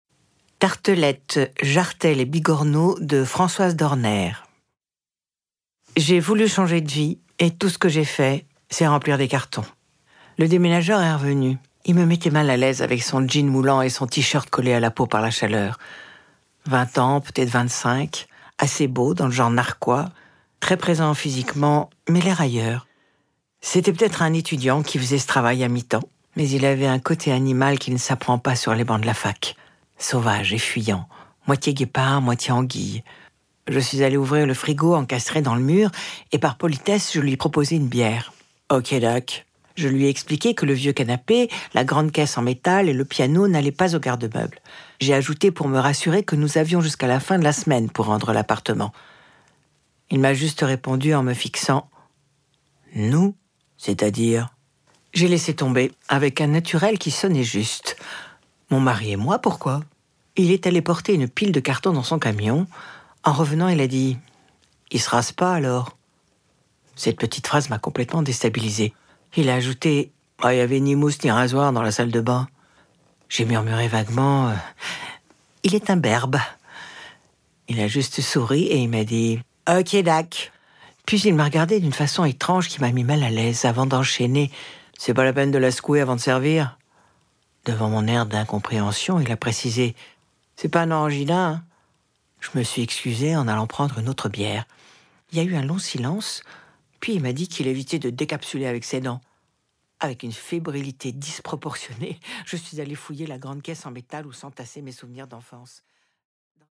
Démo Livre audio " Tartelettes et Bigorneau " voix
Voix off